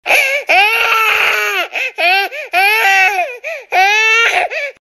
Jack-jack’s Cry (the Incredibles)